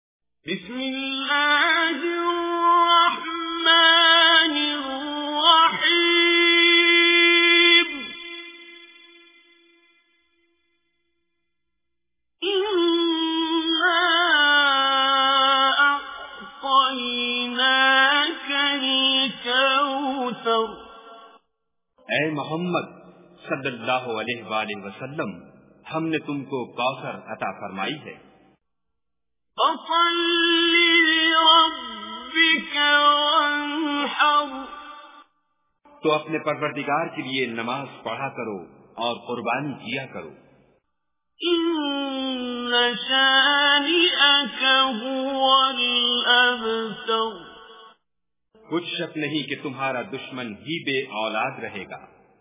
Surah Kausar Recitation with Urdu Translation